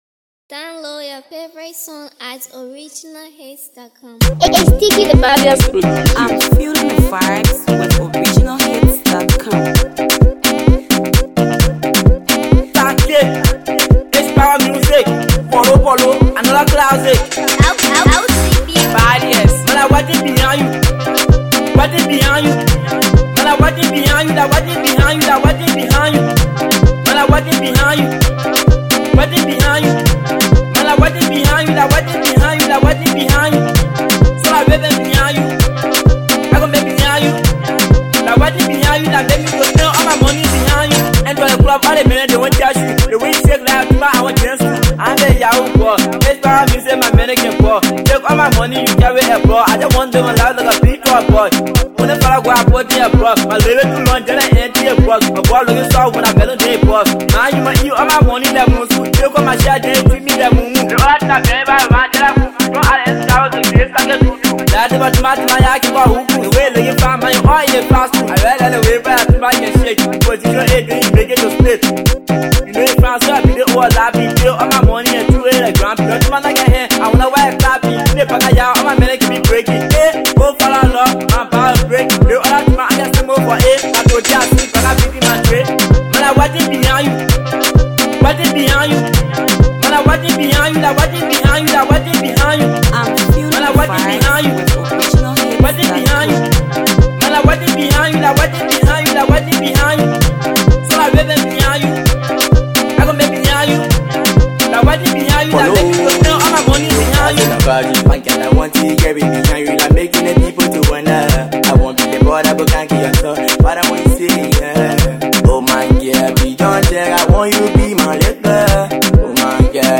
hotly danceable banger